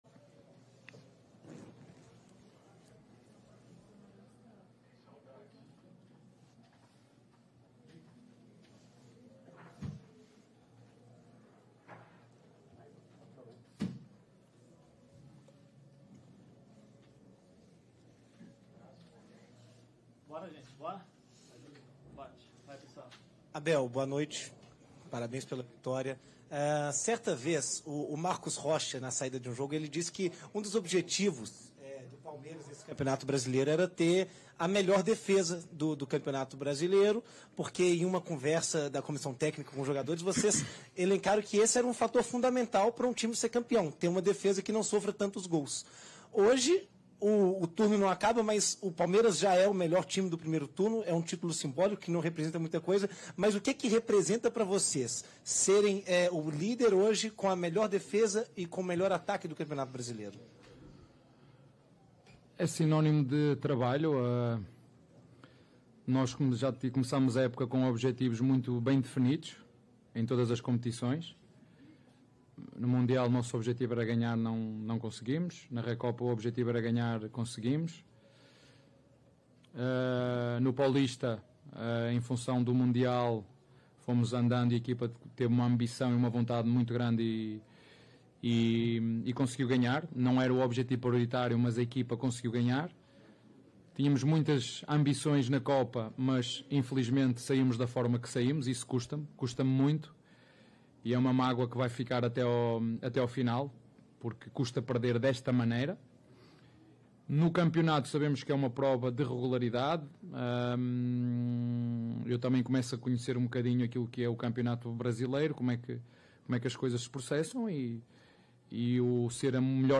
Após o triunfo, o técnico Abel Ferreira analisou o duelo com o time mineiro.